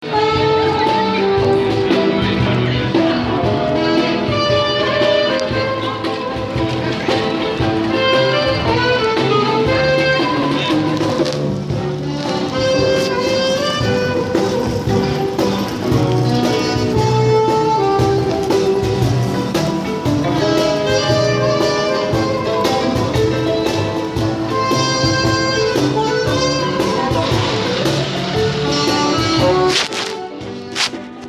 Strolling along at Grafton Street Dublin